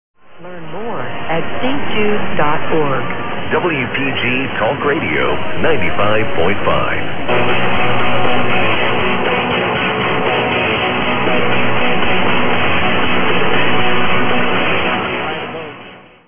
Both SDR recordings were unattended and scheduled to run between 13:30 and 17:30 EDT.
Funny to hear on some of the recordings, a live coverage of the eclipse.